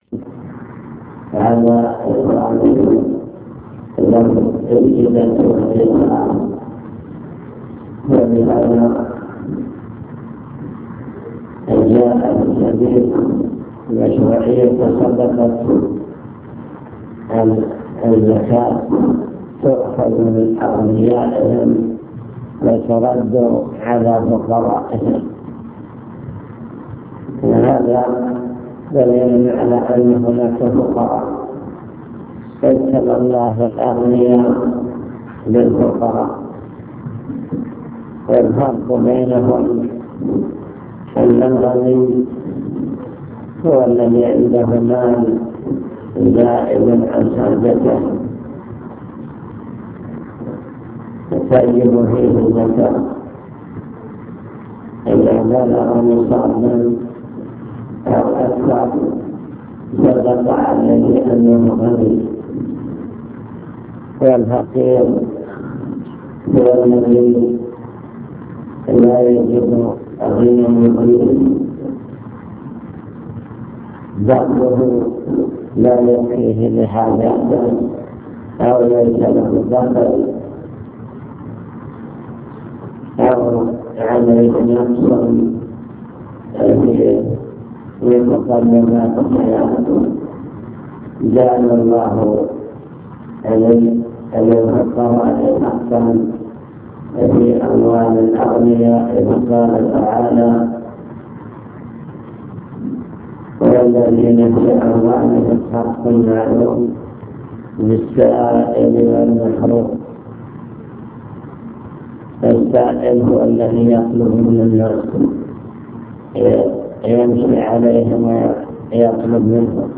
المكتبة الصوتية  تسجيلات - لقاءات  كلمة مجلس مستودع الأوقاف